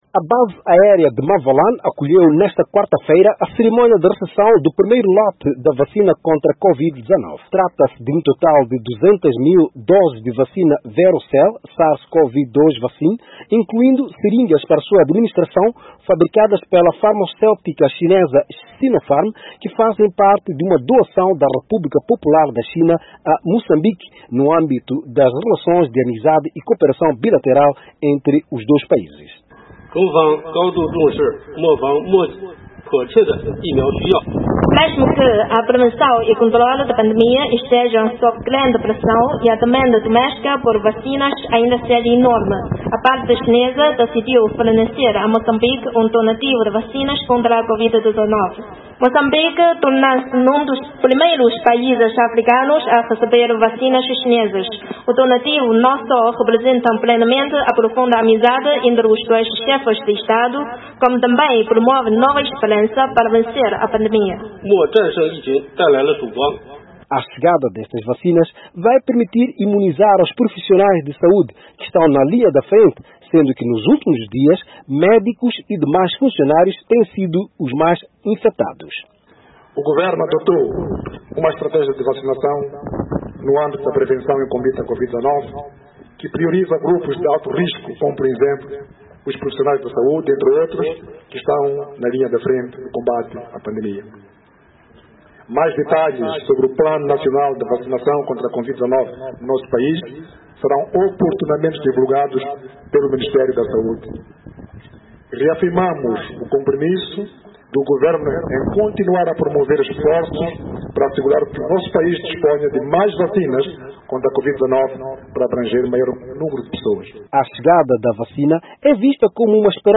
Carlos Agostinho do Rosário, primeiro-ministro, Moçambique